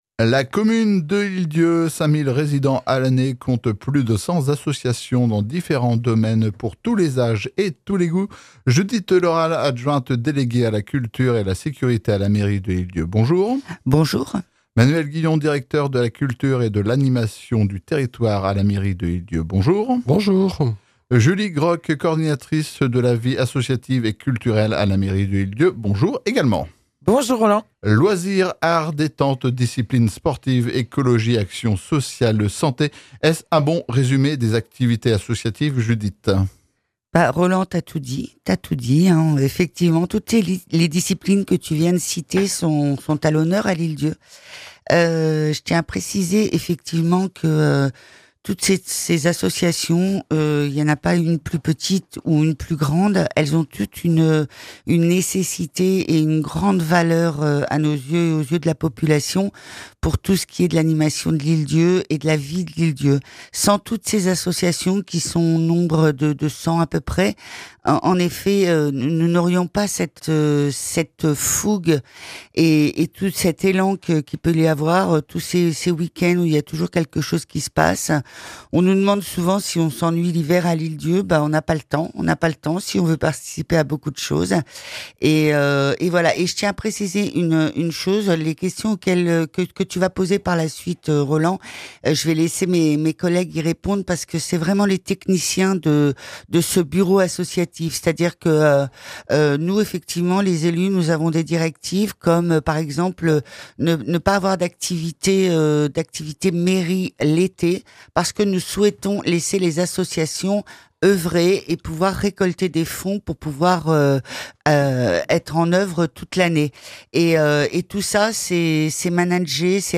Pour les aider, la Mairie accorde des subventions et met du personnel, du matériel et des salles à leur disposition. Judith Le Ralle, adjointe déléguée à la culture